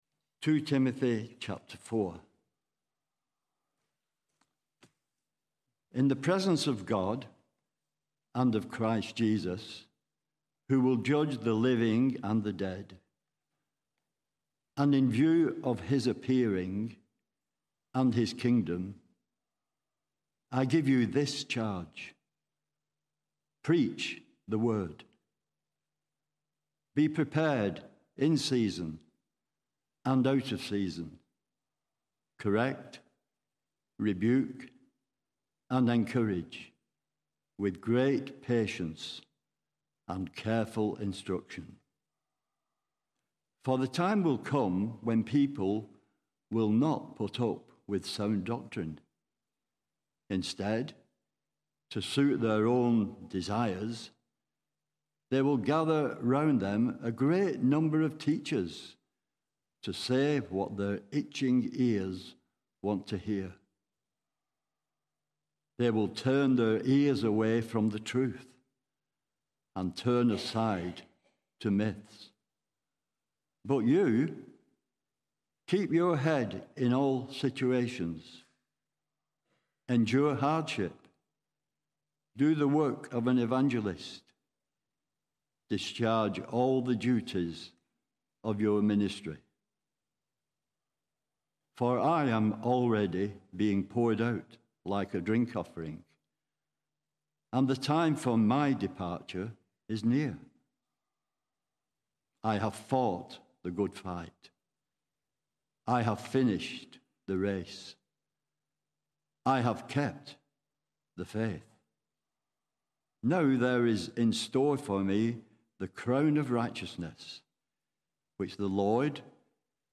Sermons Archive - Page 8 of 187 - All Saints Preston